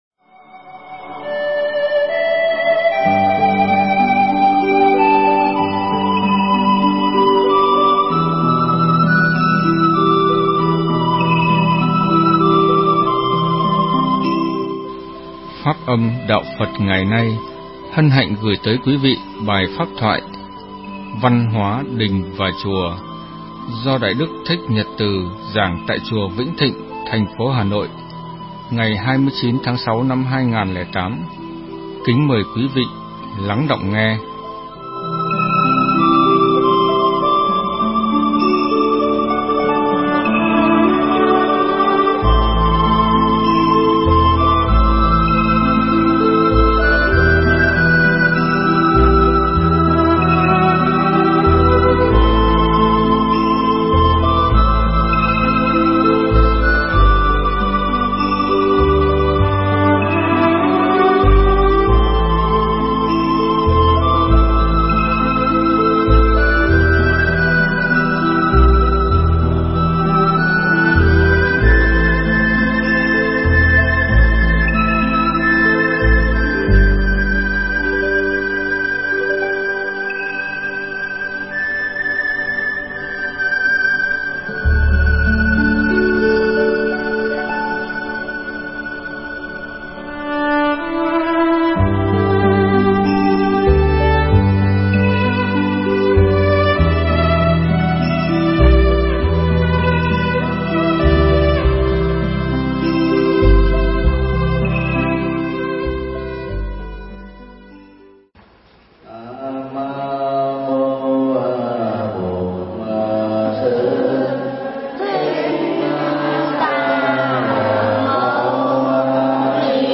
Văn hóa Đình và Chùa – Thầy Thích Nhật Từ - Mp3 thuyết pháp
Nghe mp3 pháp thoại Văn hóa Đình và Chùa được thầy Thích Nhật Từ giảng tại Chùa Vĩnh Thịnh, Hà Nội,ngày 29 tháng 06 năm 2008.